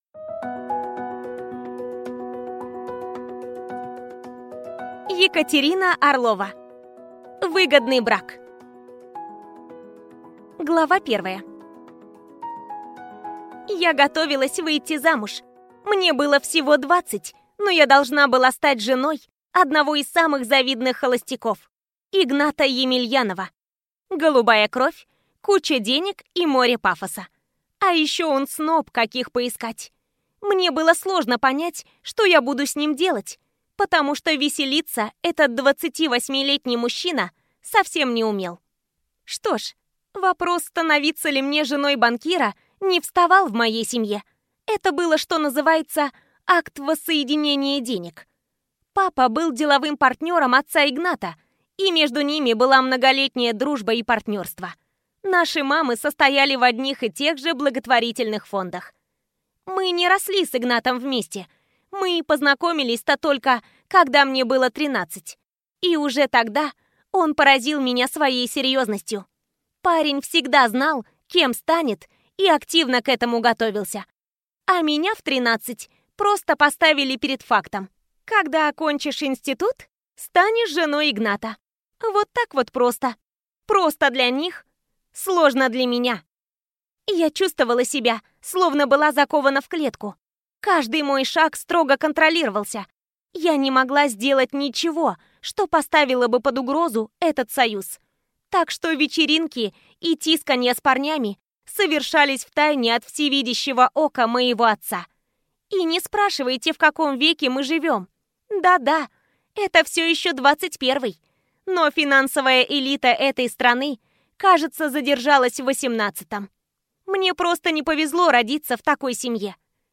Аудиокнига Выгодный брак | Библиотека аудиокниг